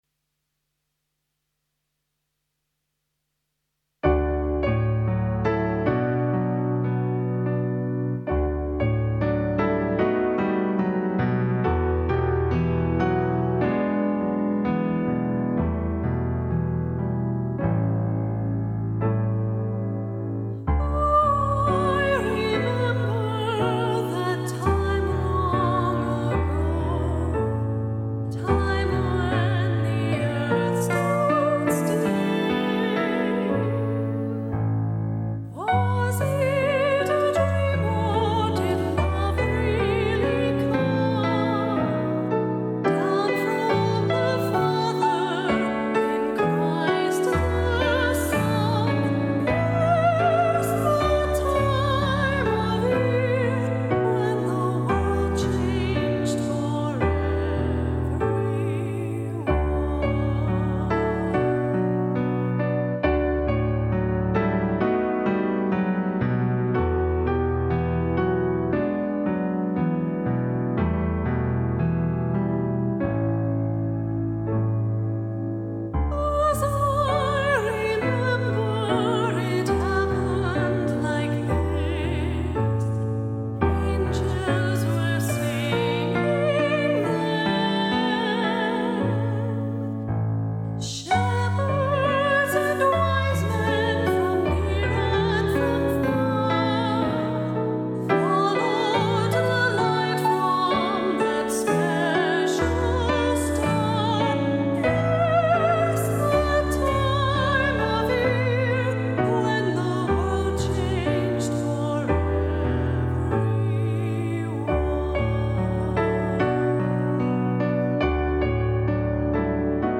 Solo / Unison voice with Keyboard accompaniment
A joyful Christmas song for solo/unison voice
with piano accompaniment.
Demonstration vedrsion sung by Mezzo-soprano